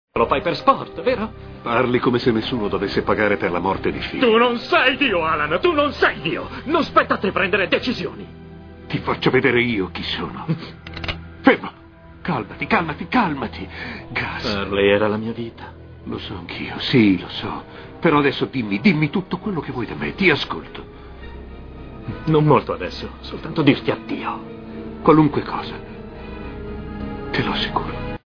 Sentieri", in cui doppia Ron Raines.